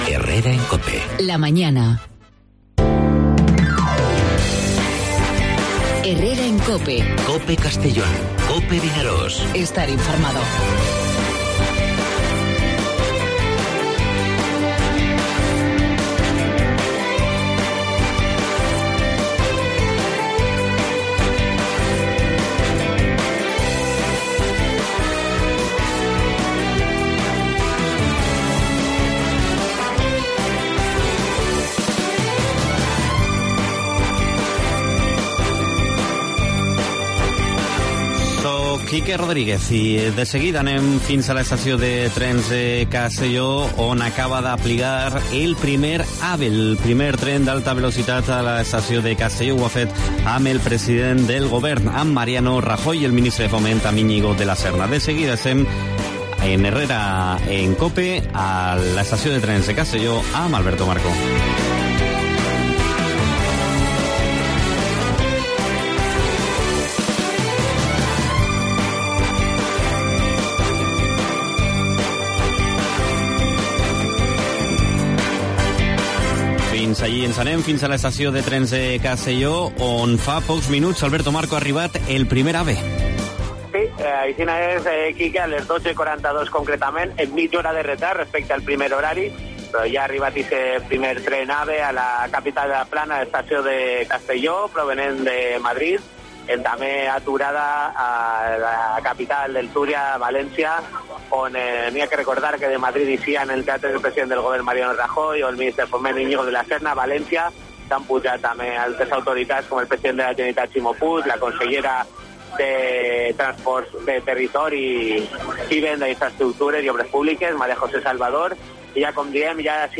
AUDIO: En Herrera en COPE Castellón nos acercamos a la estación de trenes donde el presidente del Gobierno, Mariano Rajoy, ha presidido el acto...